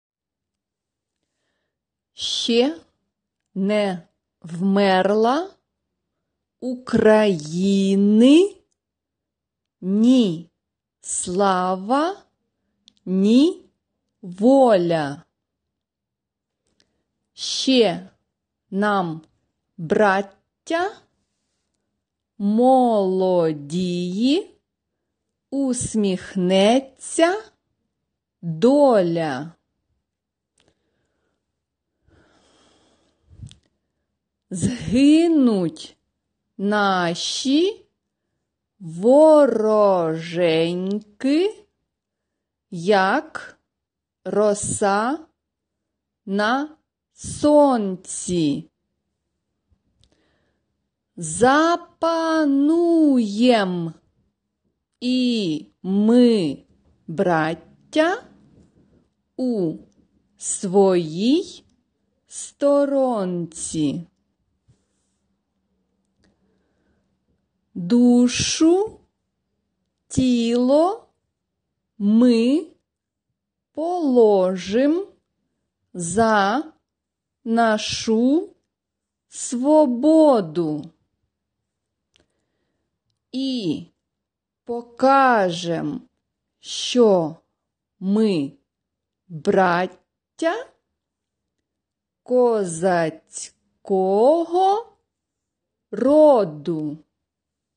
SATB (4 voices mixed) ; Full score.
Tonality: A flat major ; F minor